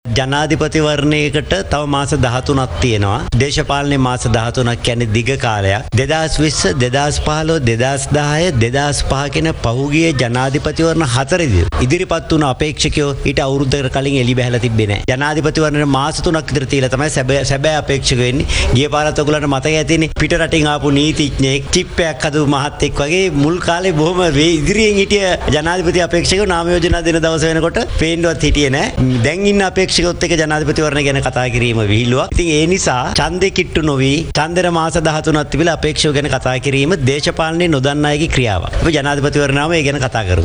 අදාළ සාකච්ඡාවෙන් අනතුරුව මාධ්‍ය වෙත අදහස් දැක්වූ පාර්ලිමේන්තු මන්ත්‍රී උදය ගම්මන්පිල මහතා.